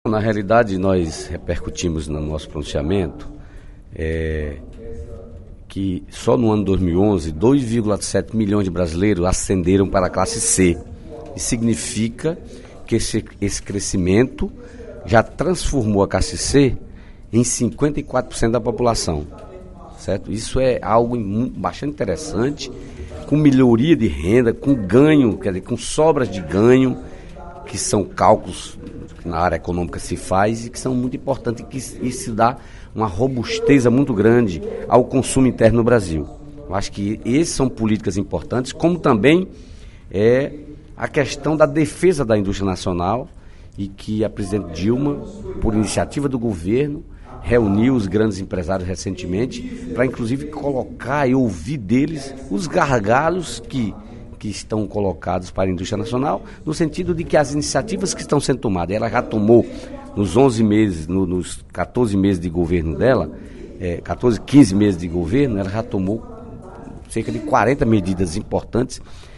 Na sessão plenária desta terça-feira (27/03), o deputado Dedé Teixeira (PT) ocupou a tribuna da Assembleia Legislativa para destacar crescimento da inclusão social no Brasil, revelado pela sétima edição da pesquisa “Observador Brasil 2012”.